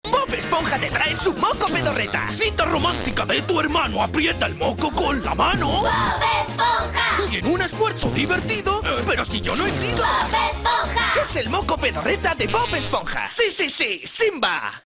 Locutor profesional, actor de doblaje y técnico de sonido con más de 10 años de experiencia en el mundo del doblaje y la locución.
kastilisch
Sprechprobe: Werbung (Muttersprache):